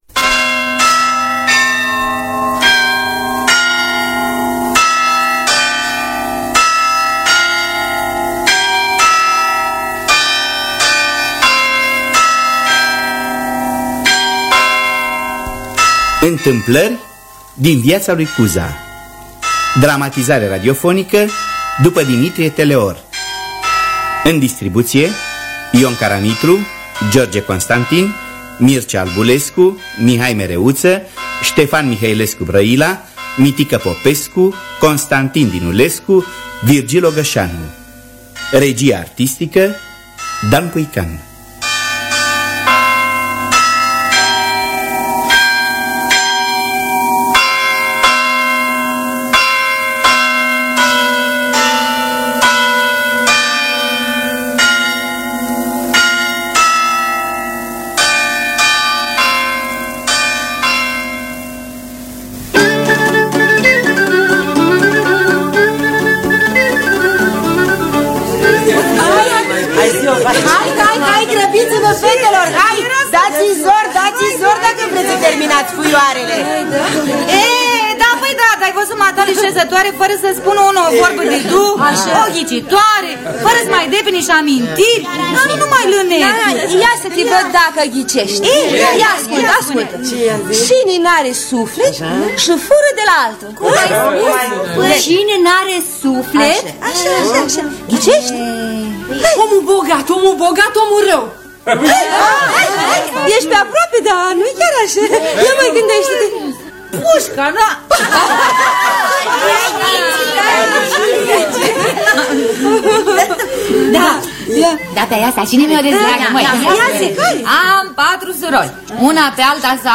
Dramatizarea radiofonică de Florea Nedelcu.